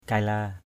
/kaɪ-la:/ 1.